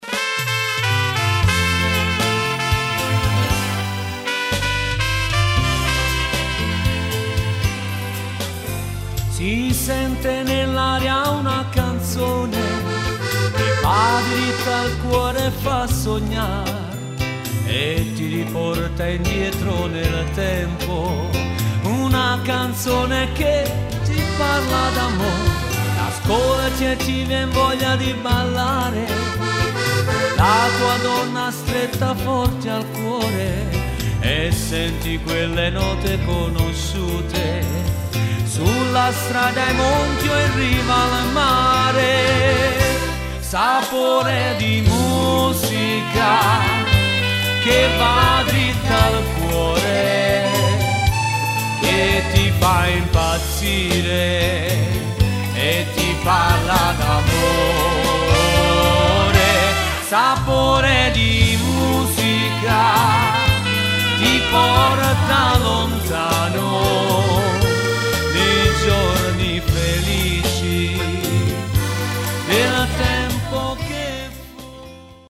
Rumba / Beguine
Uomo